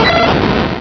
pokeemerald / sound / direct_sound_samples / cries / raikou.aif